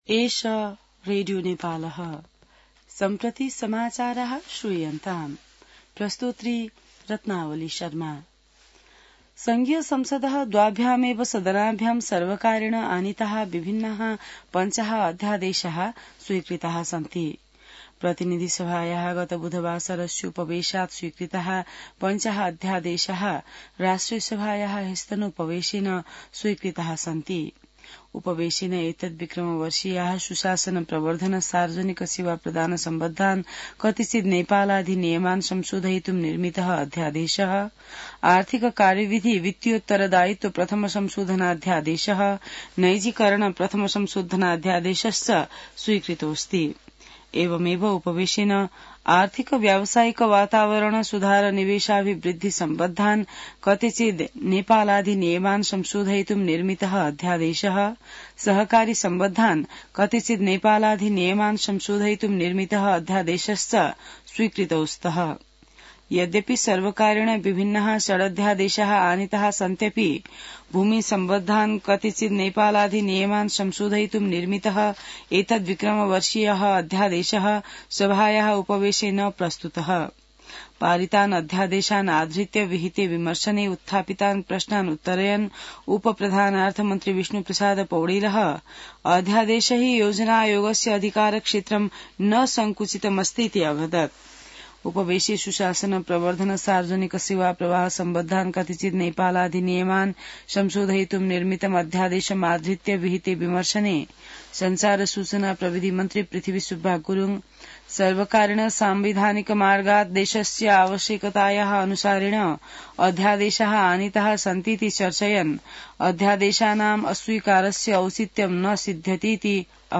संस्कृत समाचार : २४ फागुन , २०८१